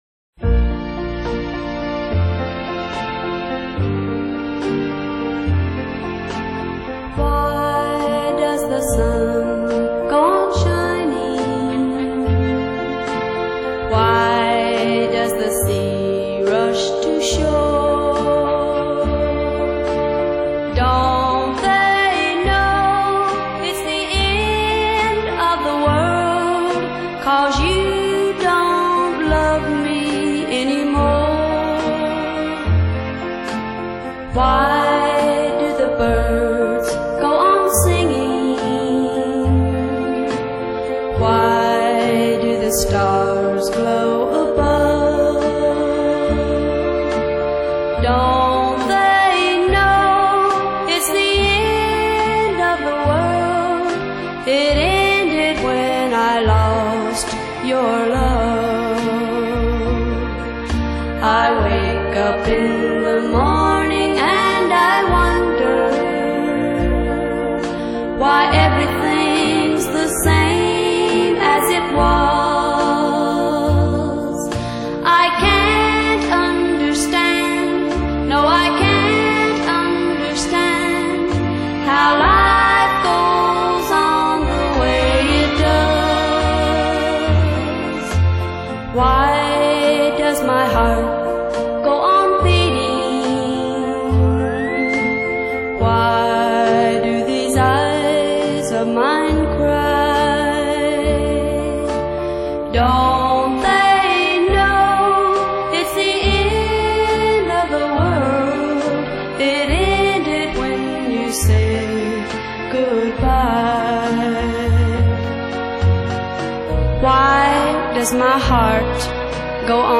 MP3 320 Kbps | Covers back&front | 120,9 Mb | Genre: Country